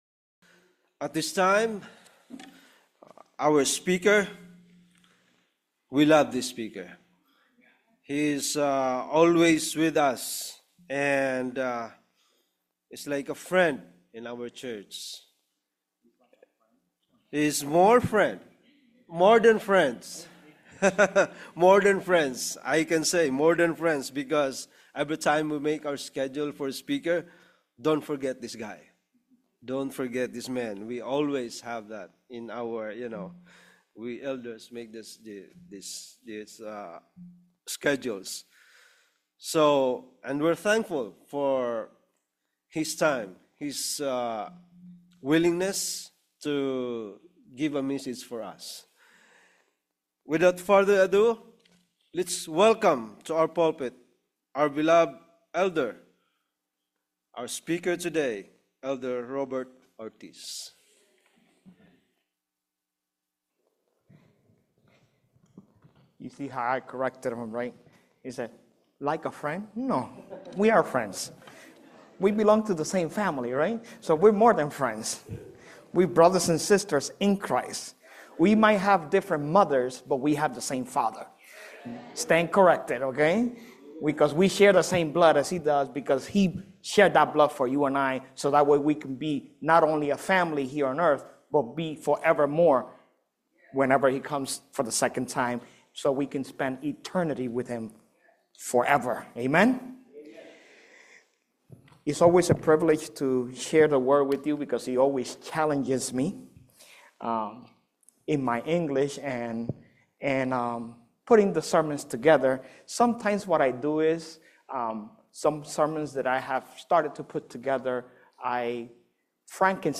Sabbath Service for January 17, 2026